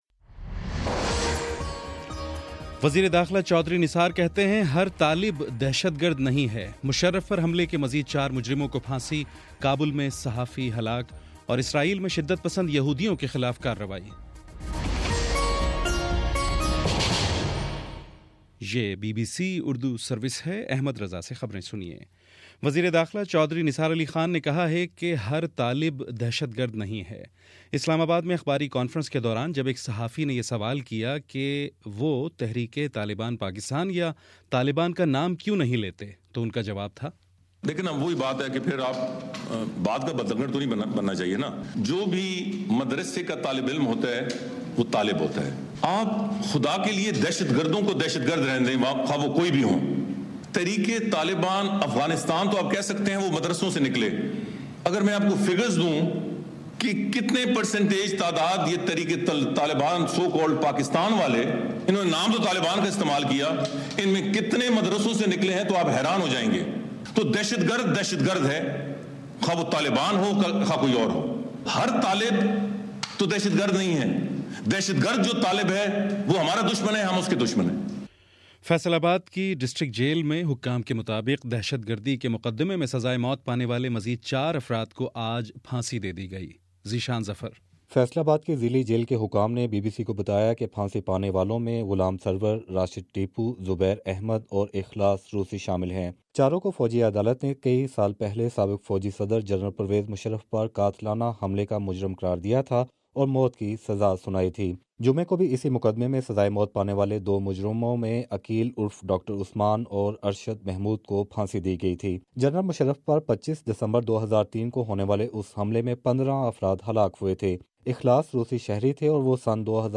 دسمبر21: شام سات بجے کا نیوز بُلیٹن